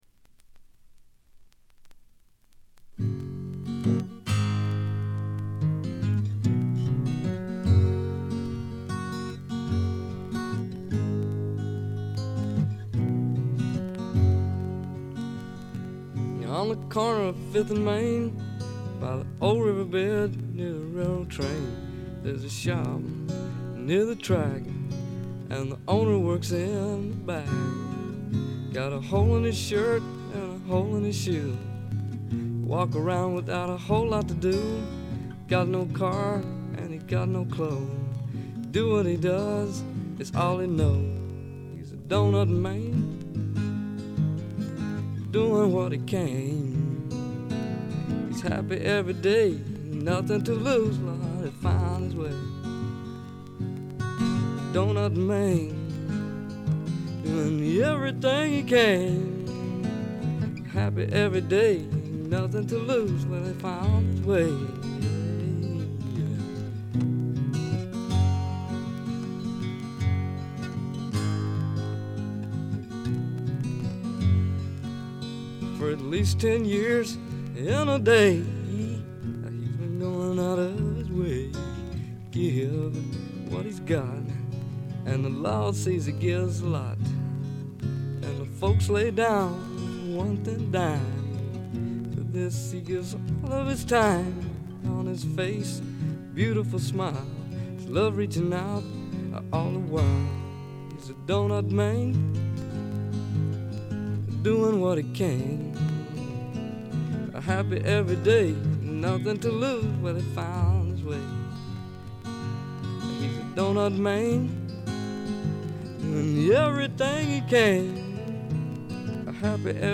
ごく微細なノイズ感のみ。
バンドサウンドとしてはこちらの方が上かな？
米国スワンプ基本中の基本！
試聴曲は現品からの取り込み音源です。